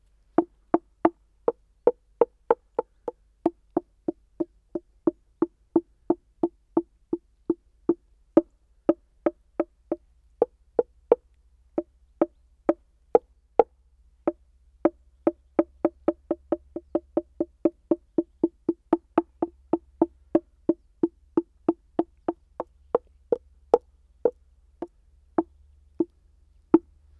Type: Fjell i dagen med klang
Dette er et ca 12 meter langt parti med åpent, flatt fjell i dagen. På noen steder er det luftlommer i fjellet som gir en hul lyd når man slår på overflata. Fjellet er i ferd med å bli overgrodd med mose, og det er nå bare et lite område av det synlige fjellet som gir klang (jf video).